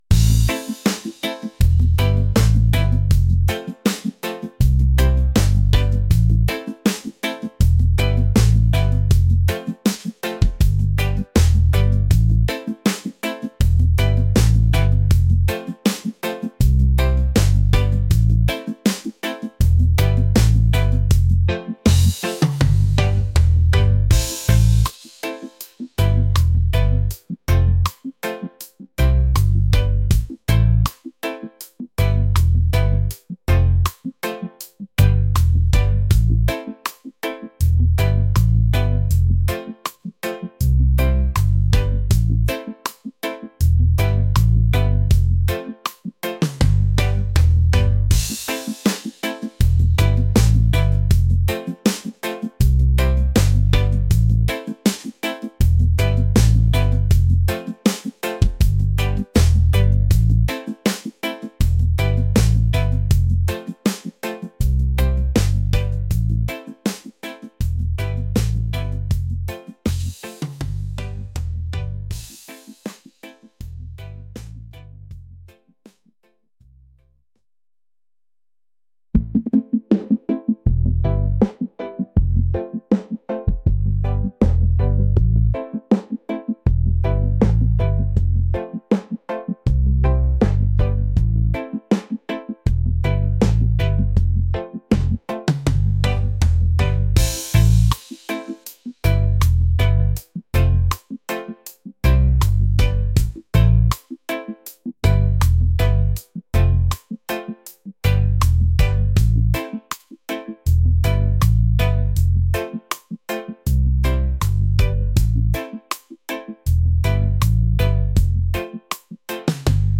reggae | romantic | laid-back